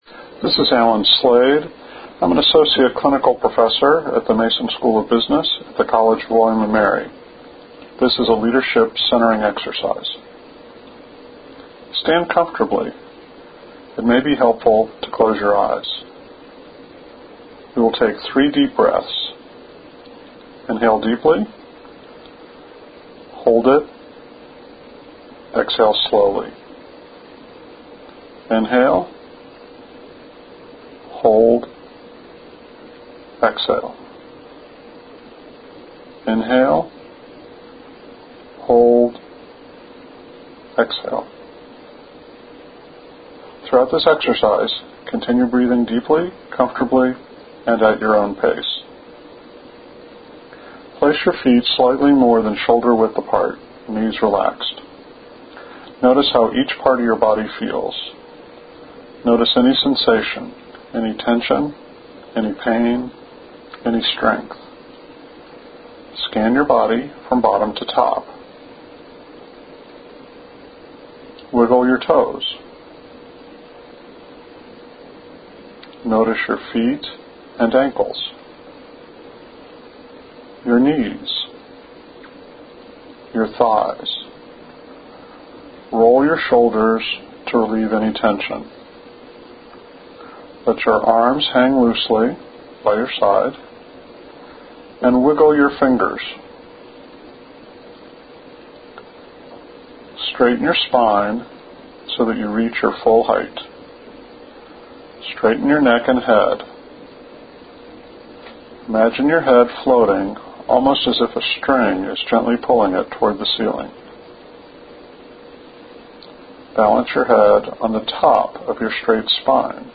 Audio instructions for leadership centering (6 min.)